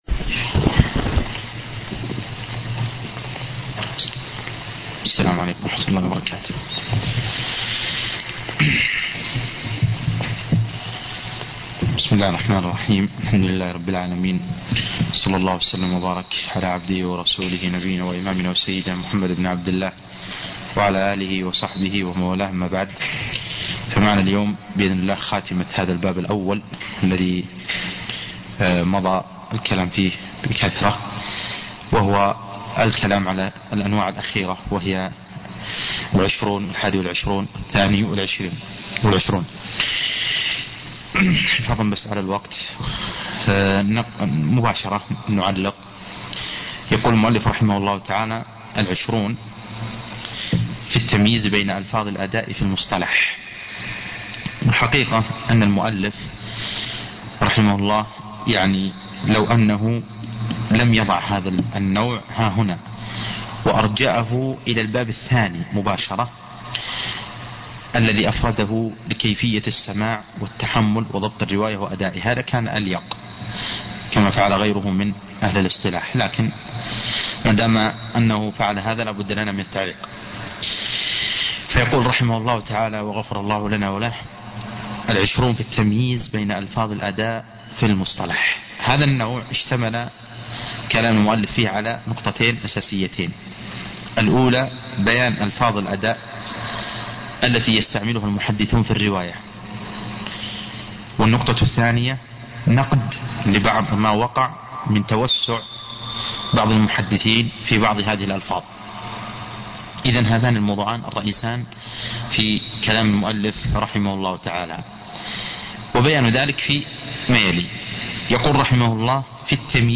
شرح الاقتراح في معرفة الاصطلاح لابن دقيق العيد